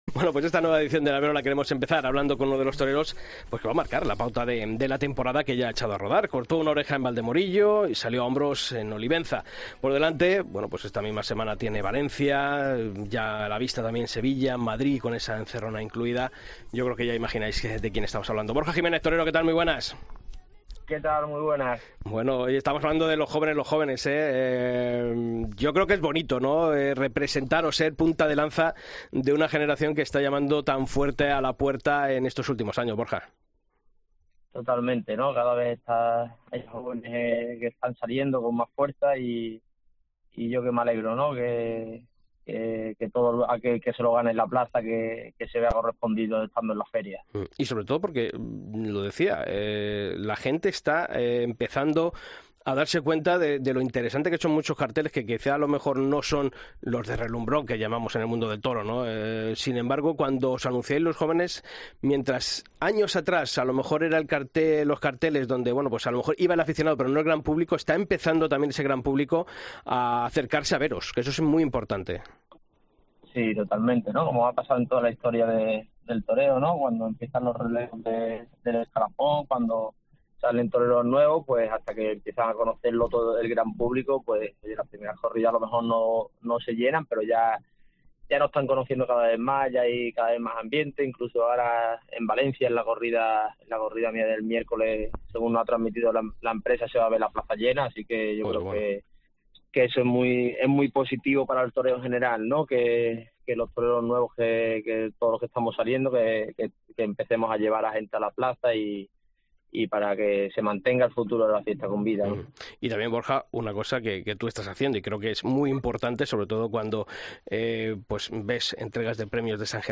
Tras cortar una oreja en Valdemorillo y salir a hombros en Olivenza , el diestro sevillano ha pasado por los micrófonos del programa El Albero para analizar un año crucial en su carrera, con compromisos inminentes en Valencia , Sevilla y una encerrona en Madrid .